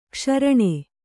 ♪ kṣaraṇe